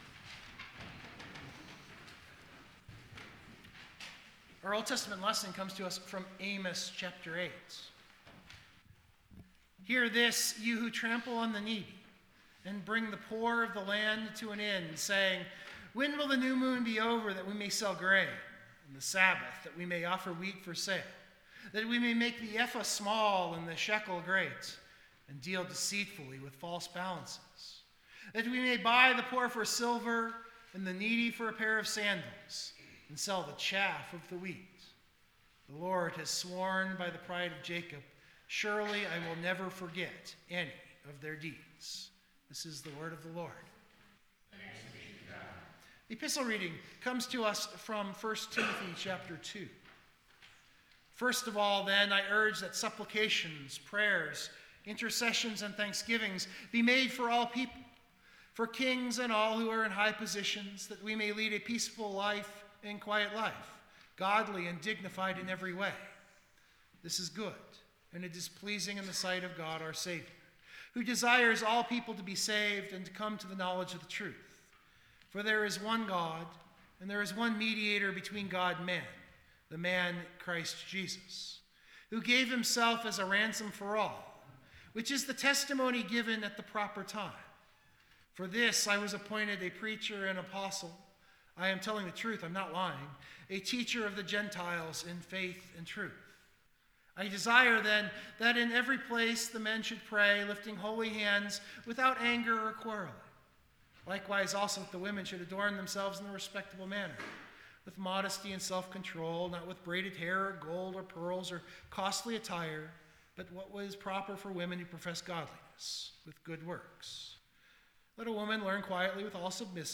I’m not sure a recording happened this week, and I don’t have my good mike yet to record it after the fact.
Full Sermon Draft Download Biblical Text: Luke 16:1-15 Most weeks it takes translating, a little reading and a little pondering to come up with a sermon idea.